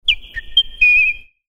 Download Penninger Zwitschern
penninger_klingelton_zwitschern_neu.mp3